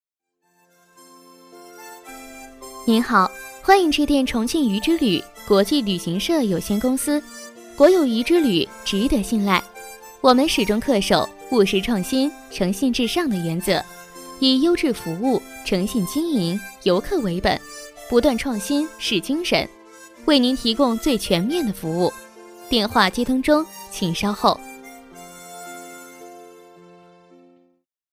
女285-电话彩铃【旅行社彩铃 轻松活跃】
女285-明亮柔和 轻松活泼